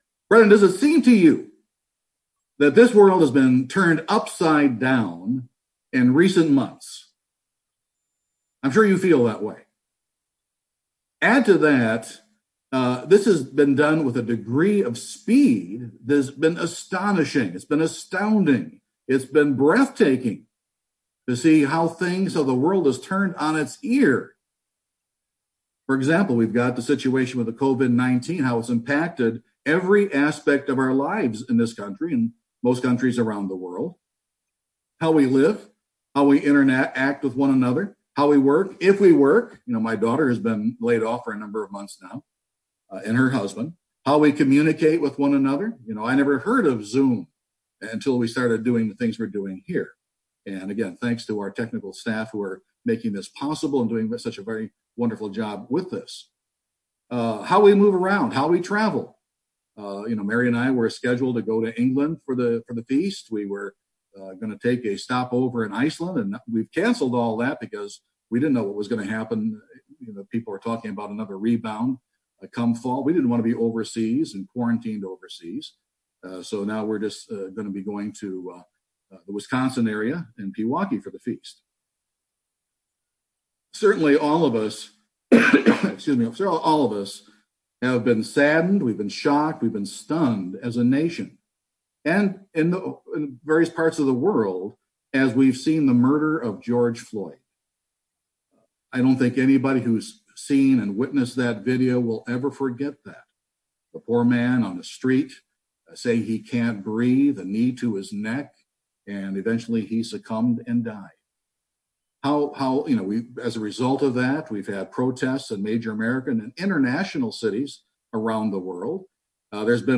What is misdirection, and how does Satan try and use it to spiritually injure you? This sermon goes through to explain this.